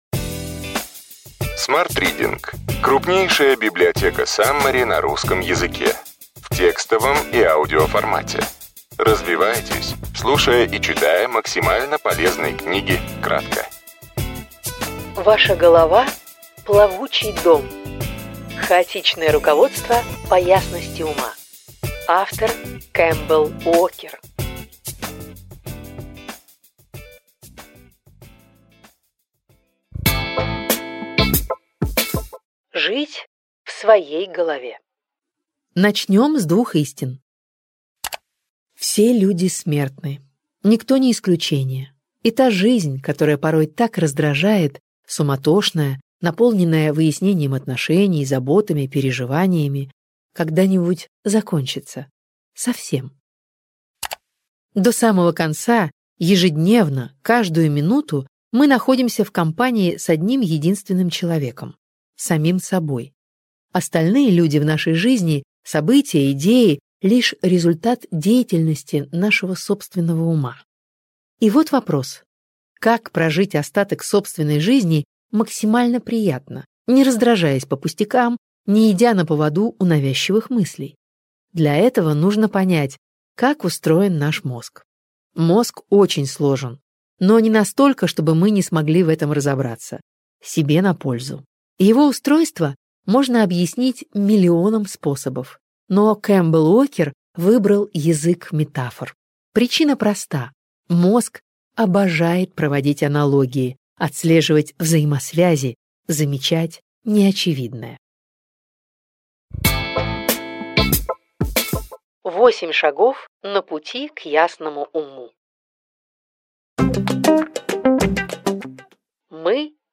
Аудиокнига Ключевые идеи книги: Ваша голова – плавучий дом. Хаотичное руководство по ясности ума.
Читает аудиокнигу